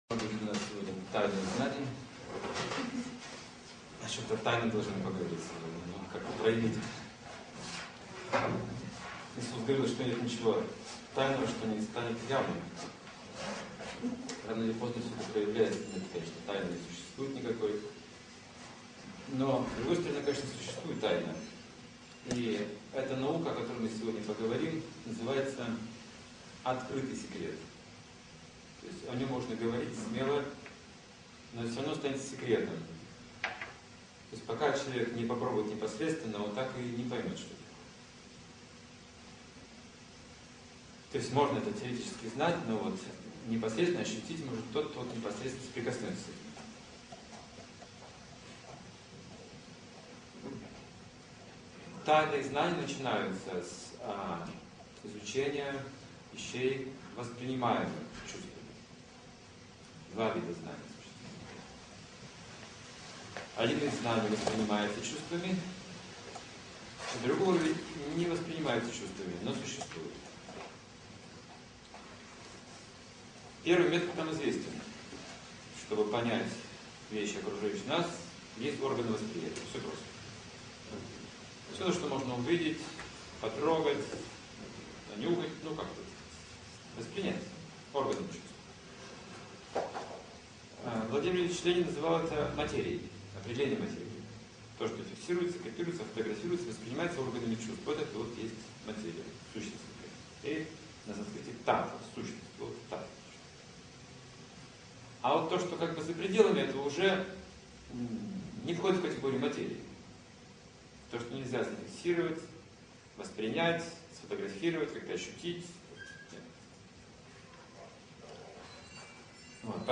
Лекция о том, как прийти к божественной любви, которая не заканчивается, а только возрастает и усиливается, даруя нам настоящее счастье.